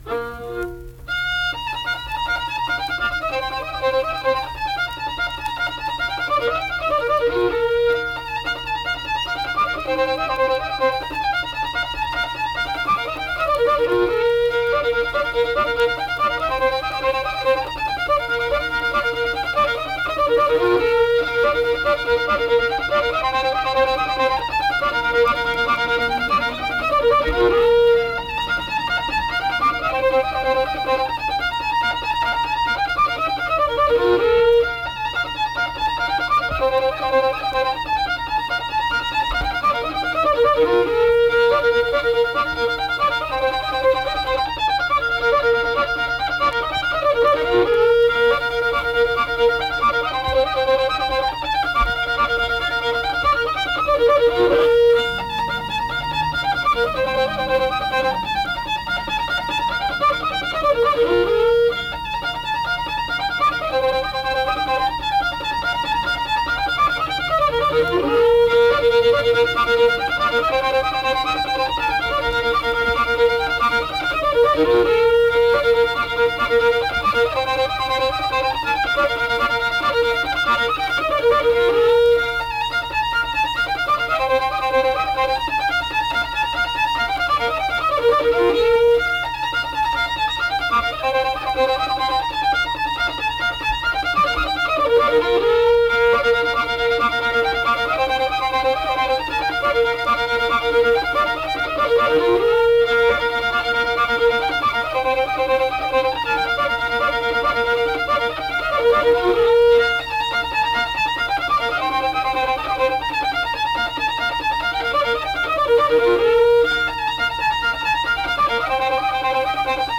Unaccompanied fiddle music and accompanied (guitar) vocal music
Instrumental Music
Fiddle